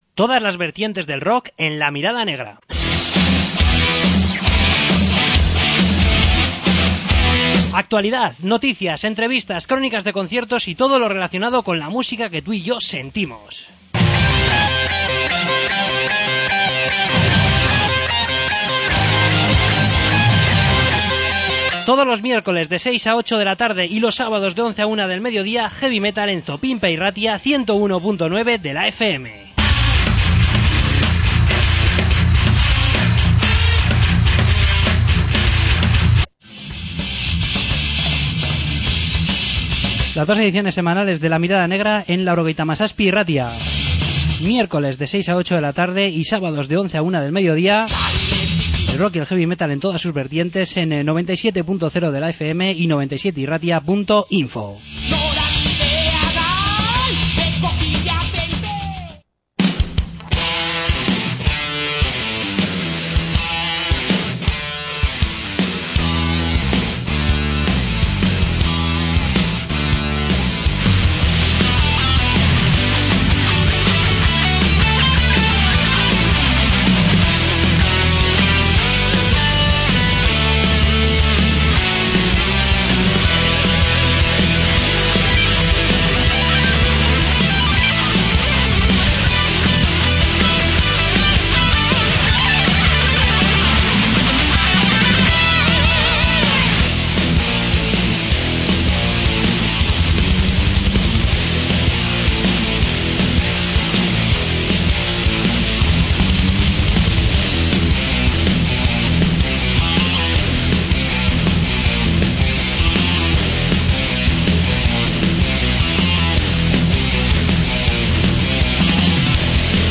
Entrevista con The Foxholes